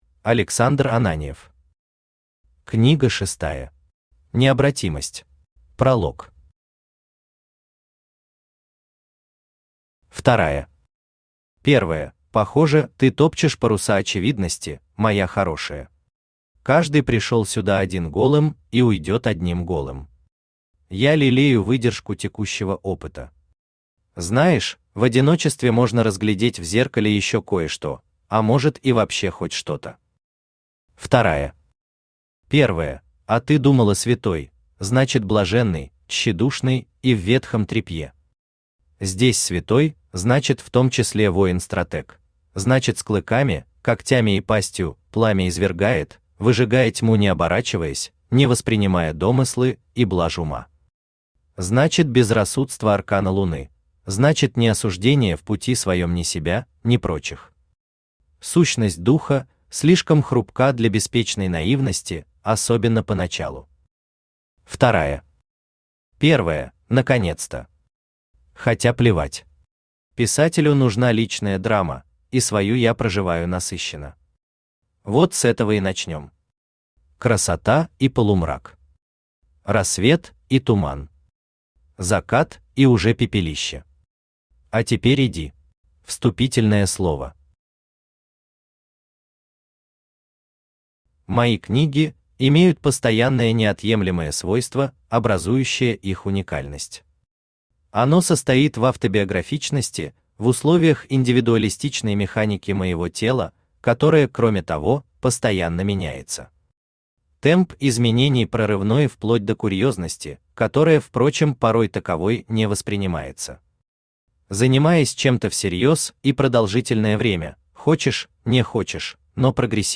Аудиокнига Книга шестая. Необратимость | Библиотека аудиокниг
Необратимость Автор Александр Алексеевич Ананьев Читает аудиокнигу Авточтец ЛитРес.